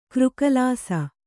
♪ křkalāsa